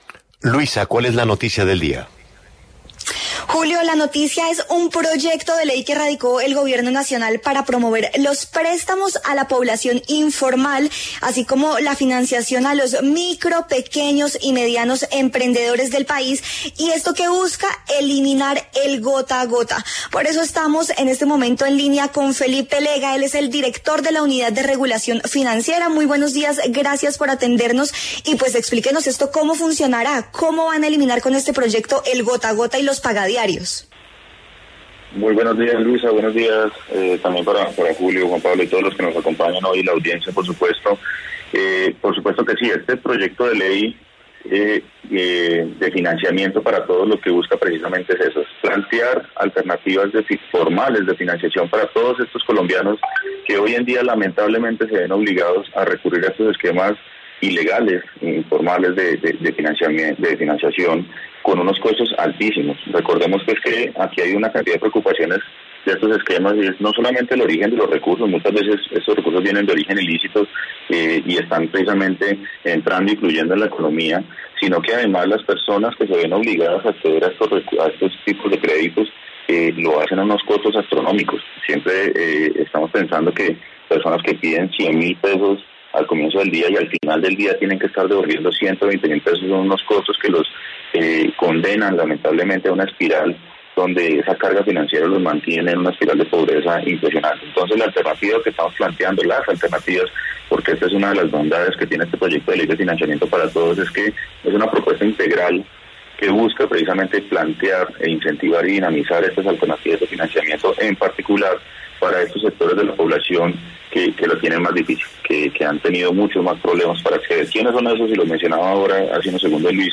En diálogo con La W, Felipe Lega, director de la URF, explicó de dónde saldrán los recursos para las garantías a estos préstamos. Además, anunció que se plantean subsidios.